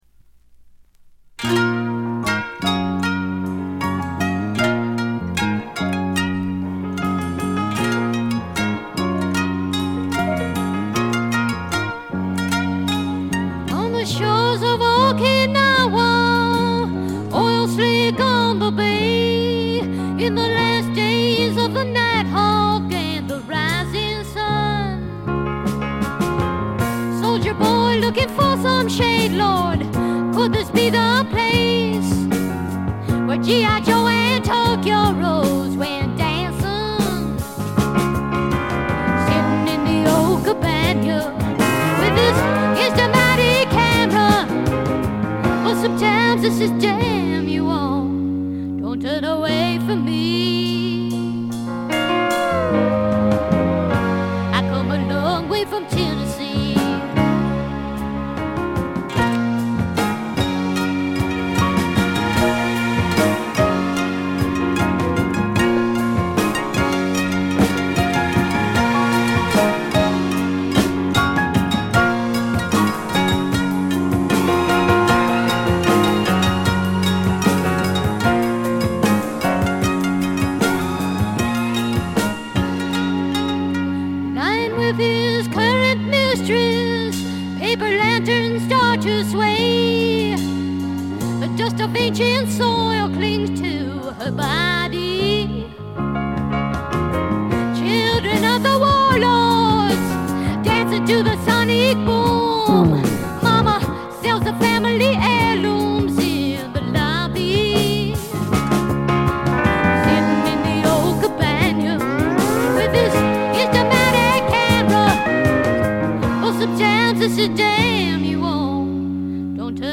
全曲自作で良曲が並び、リリカルなピアノとコケティッシュなヴォーカルがとても良いです。
試聴曲は現品からの取り込み音源です。